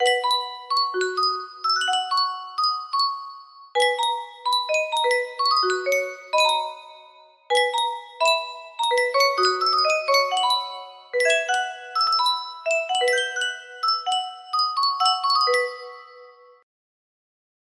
Yunsheng Music Box - Unknown Tune 1728 music box melody
Full range 60